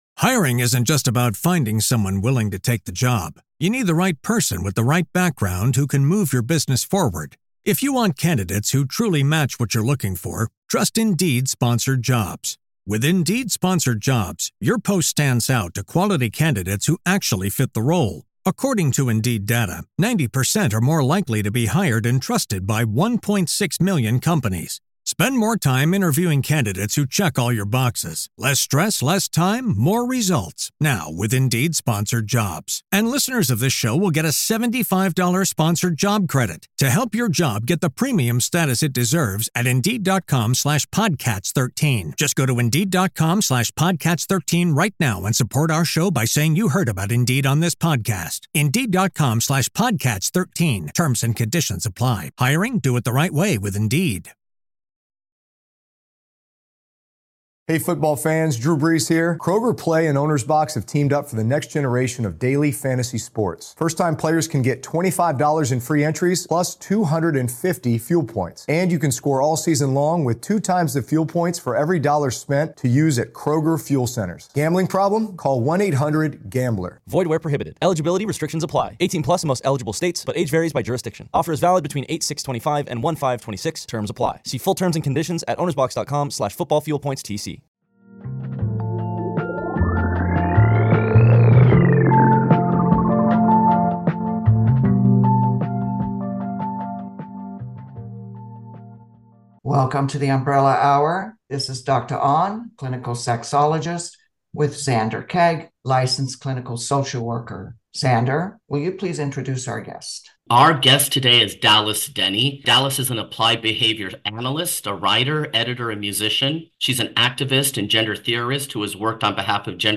and inspiring interviews that examine the lives of LGBT people from diverse vantage points.